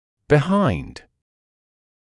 [bɪ’haɪnd][би’хайнд]за, сзади, позади